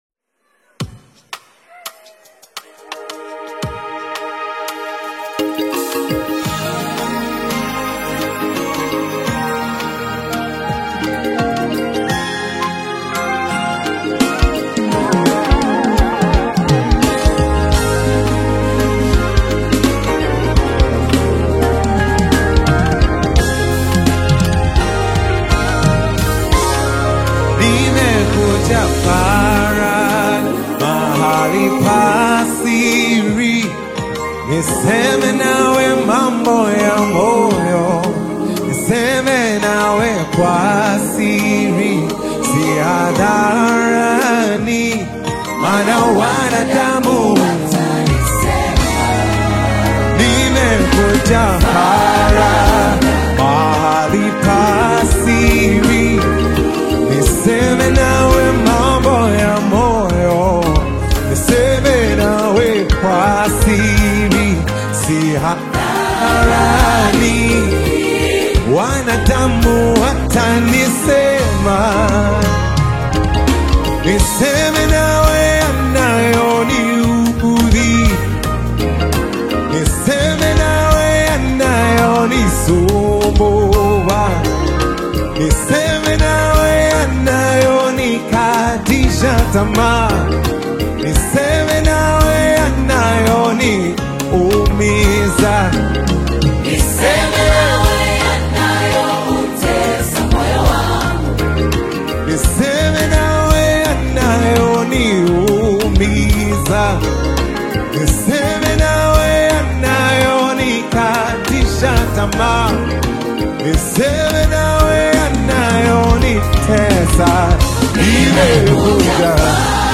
AudioGospelUniversal